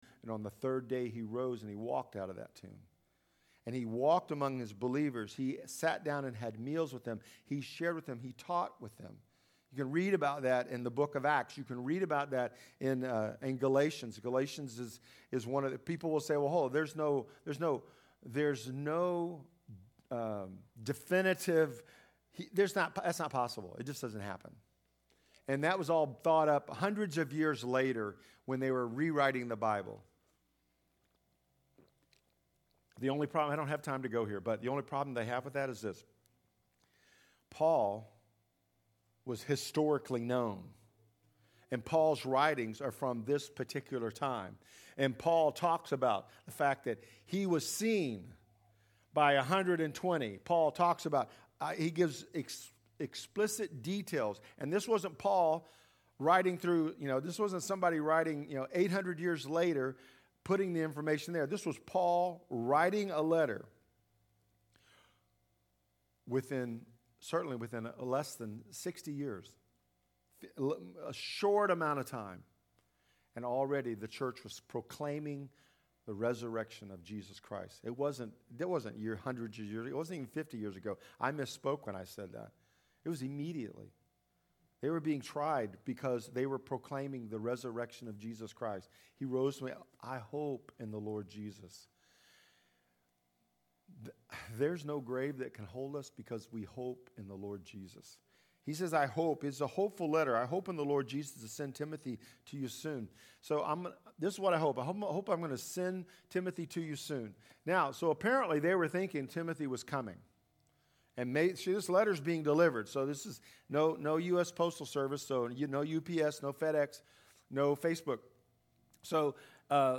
I Choose Joy-Preaching Through Philippians – First Baptist Church Oak Grove